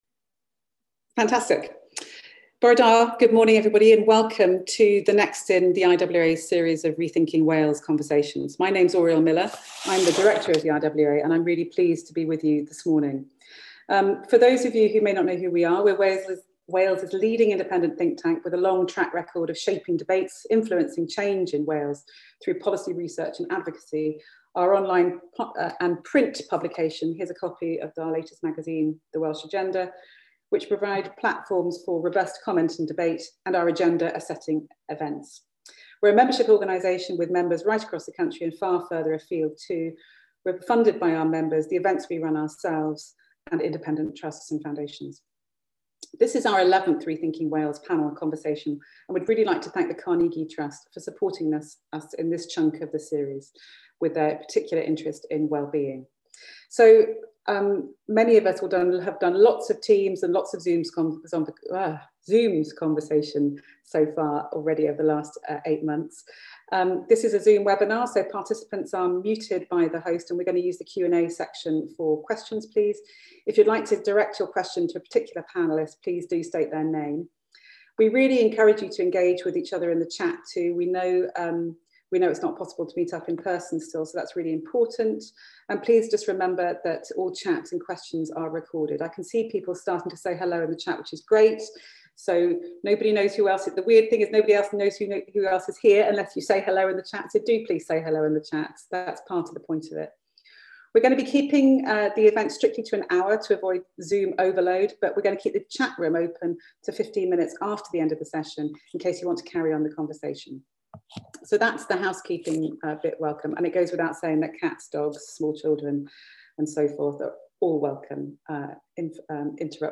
Panel session (approximately 30 minutes) Q&A session with audience (approximately 15 minutes)